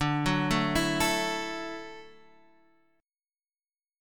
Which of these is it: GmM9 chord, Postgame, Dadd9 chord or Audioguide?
Dadd9 chord